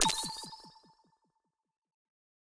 overlay-pop-out.wav